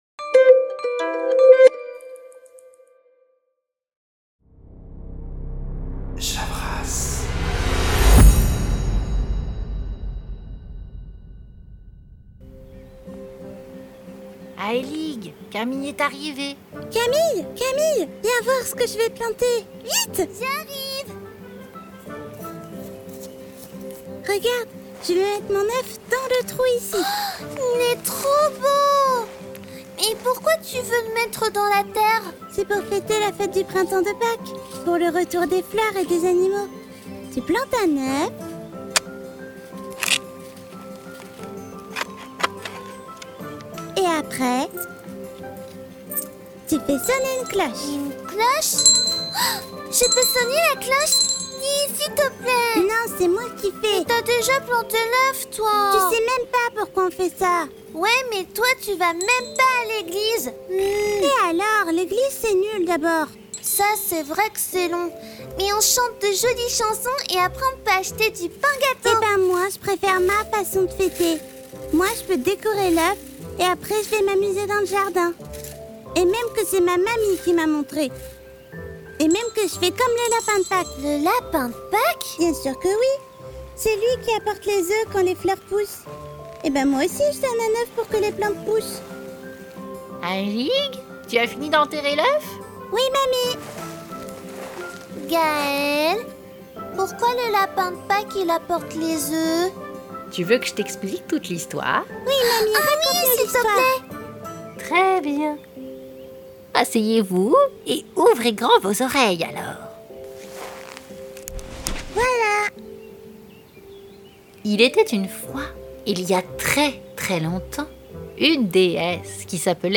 Fictions Sonores et Poésies
Figuration de voix d’enfants
cloche Easter Bells